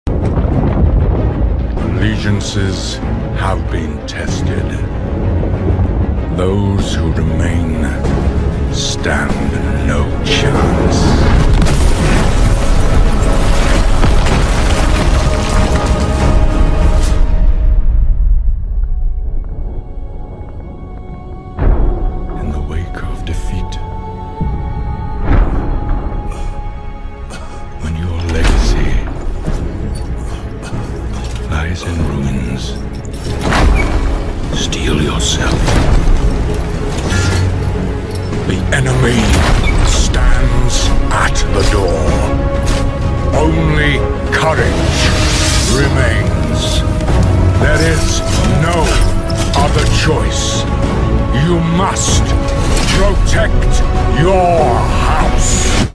⚔ SFX & mix session sound effects free download